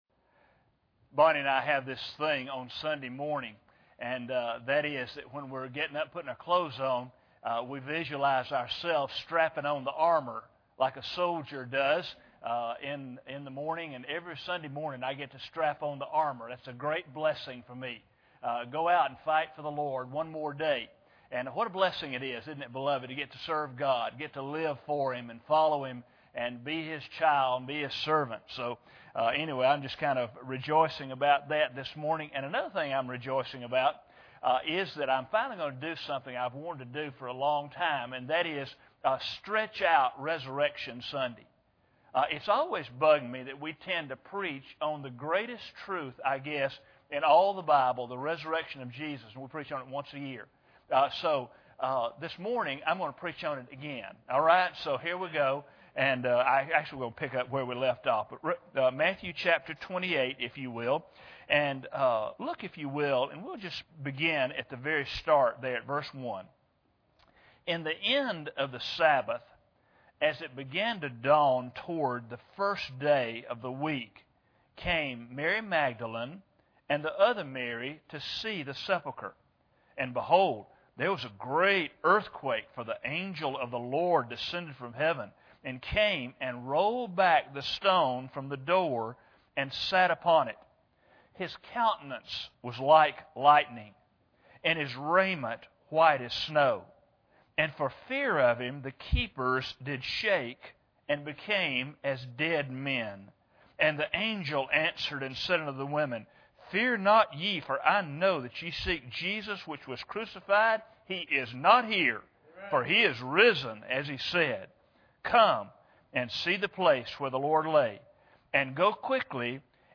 Matthew 28:1-9 Service Type: Sunday Morning Bible Text